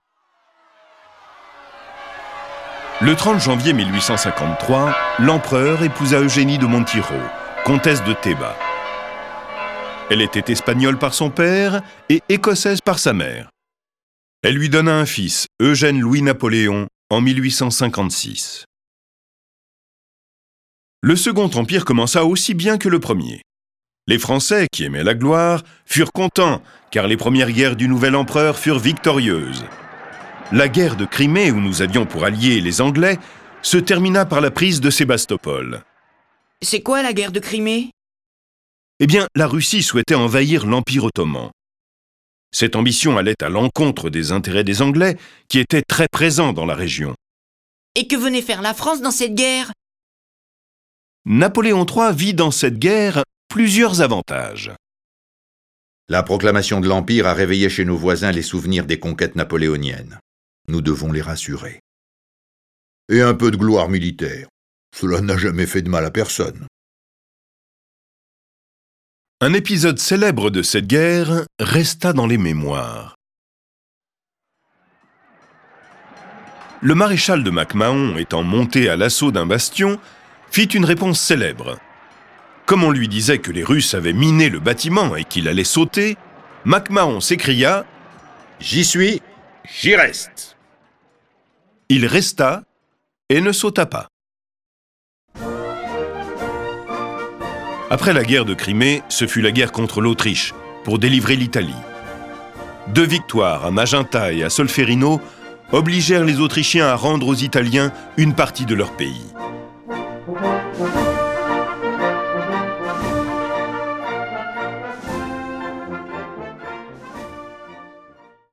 Diffusion distribution ebook et livre audio - Catalogue livres numériques
Cette version sonore de ce récit est animée par dix voix et accompagnée de plus de trente morceaux de musique classique.